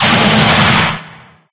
Fire7.ogg